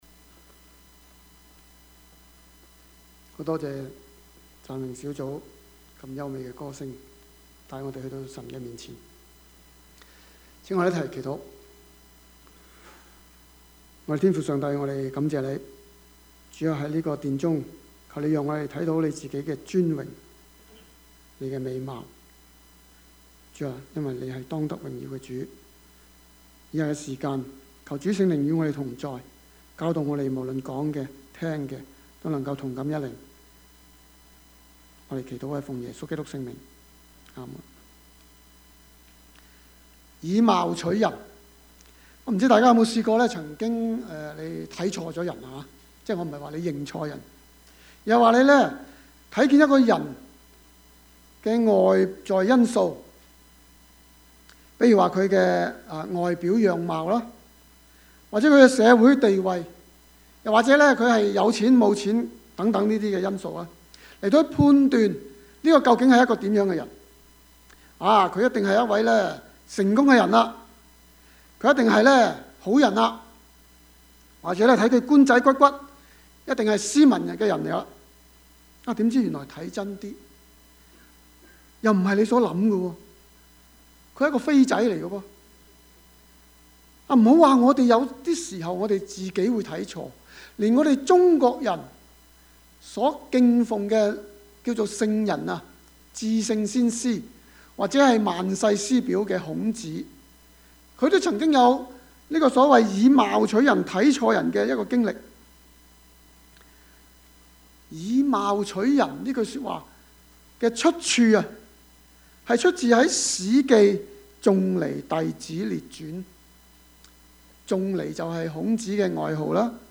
Service Type: 主日崇拜
Topics: 主日證道 « 以貎取人 權‧名‧錢 »